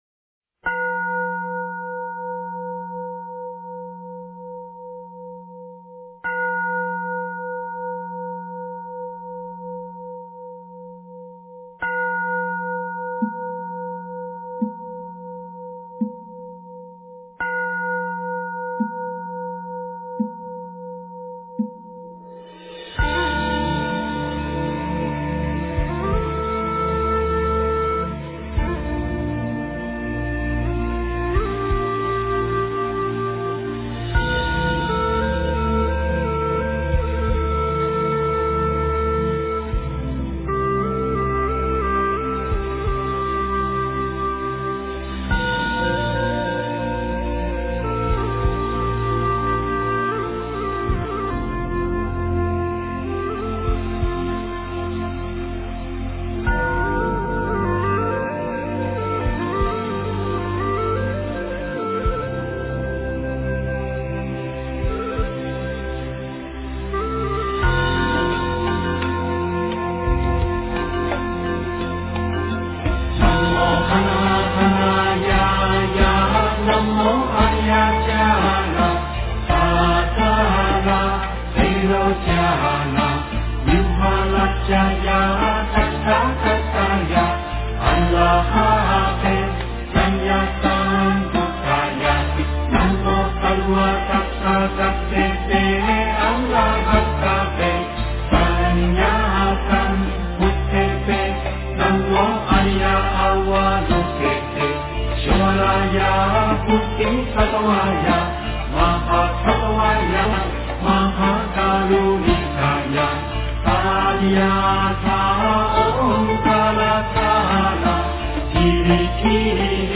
大悲咒（藏音）
诵经
佛教音乐